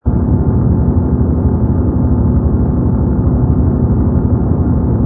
rumble_pi_fighter.wav